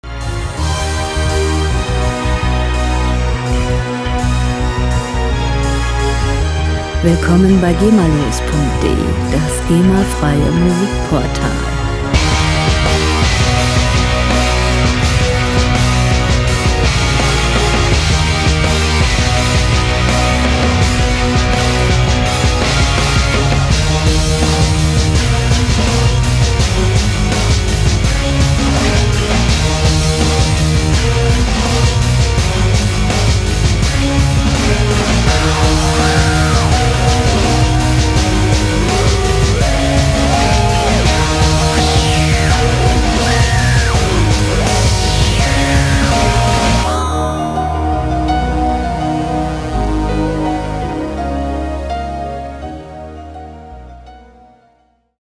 lizenzfreie Werbemusik für Imagefilme
Musikstil: Bombast Rock
Tempo: 83 bpm
Tonart: Es-Moll
Charakter: wuchtig, groß
Instrumentierung: E-Gitarre, E-Bass, Drums, Orchester